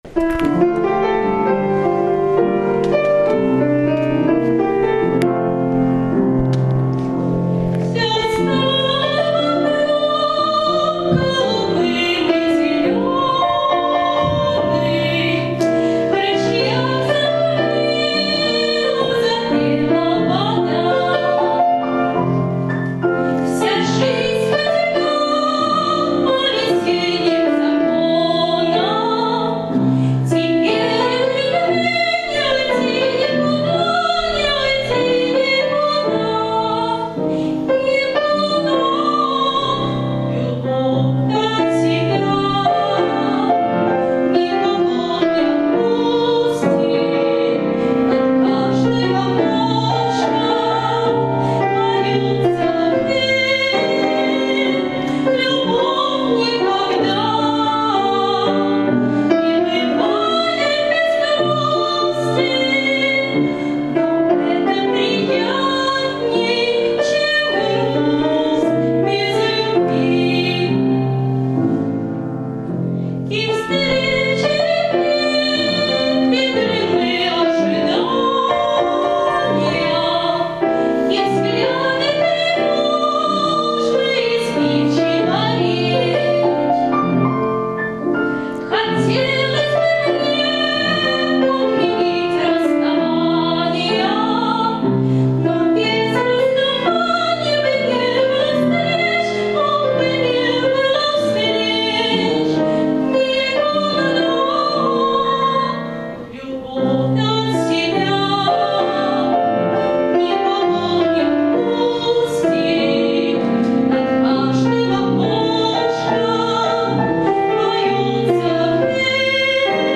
Советское Лирика Ретро Живой звук